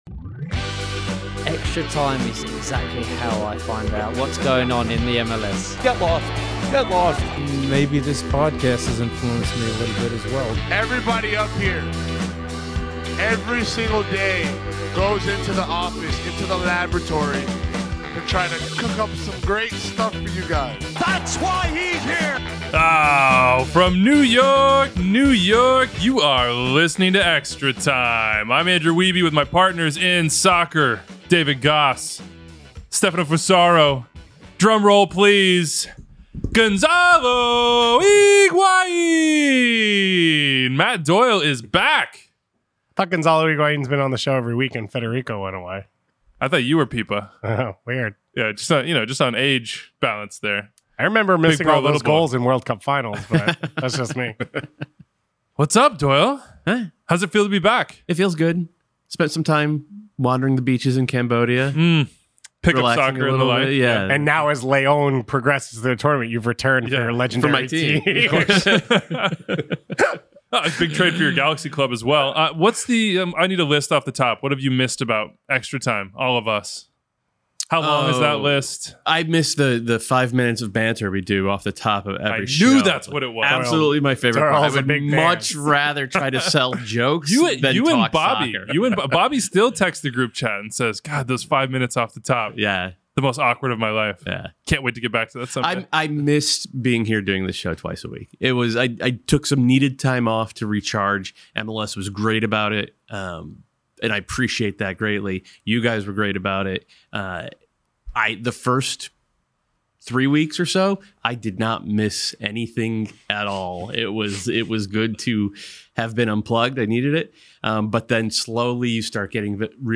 The guys gather to wrap up the Leagues Cup group stage, including a very strange day for the Crew. We run through the best things we saw, dig into the Lucas Zelarayan farewell and Diego Fagundez trade and rank the Knockout Round regions.